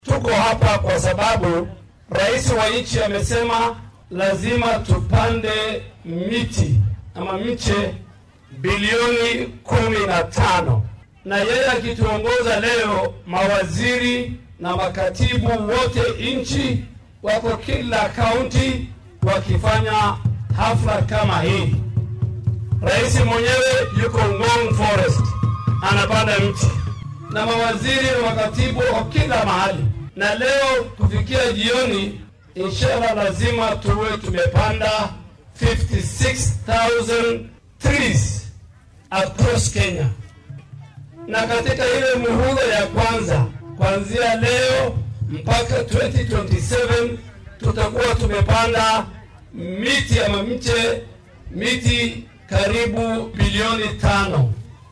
Hadalkan ayuu ka jeediyay xaafadda Iftiin ee Garissa oo uu ka daahfuray ololaha geedo lagu abuuraya.